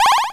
snd_win.mp3